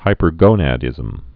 (hīpər-gōnăd-ĭzəm, -gŏnə-dĭzəm)